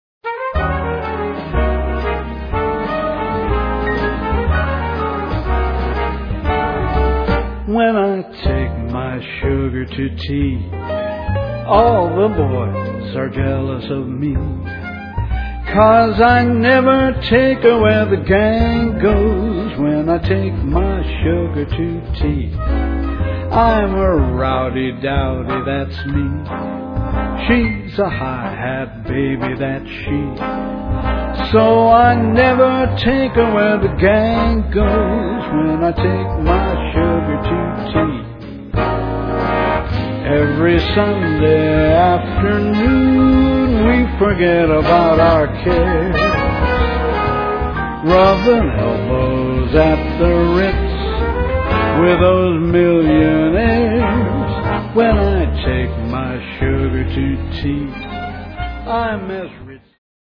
vocals
saxes, piano, trumpet
flute
trombones
bass
drums
strings